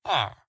Minecraft Version Minecraft Version 1.21.5 Latest Release | Latest Snapshot 1.21.5 / assets / minecraft / sounds / mob / villager / idle3.ogg Compare With Compare With Latest Release | Latest Snapshot